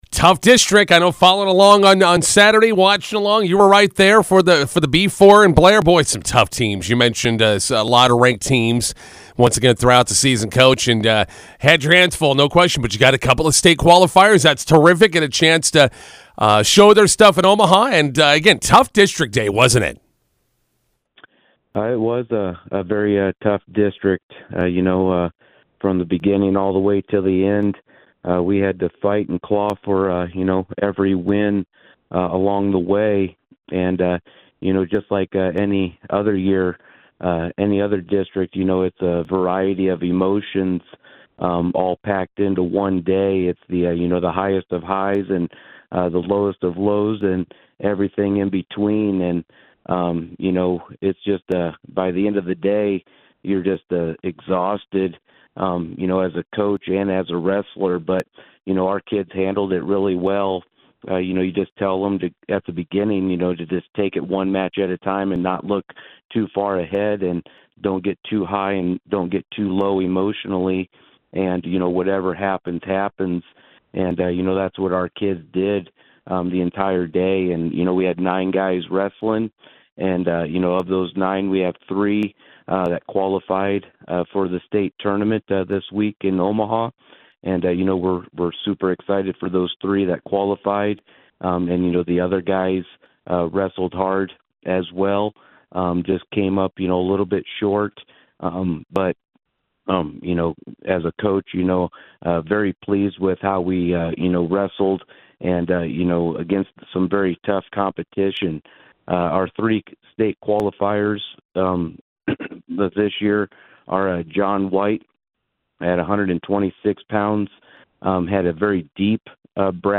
INTERVIEW: Bison wrestlers finish eighth at B4 district in Blair.